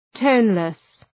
Προφορά
{‘təʋnlıs}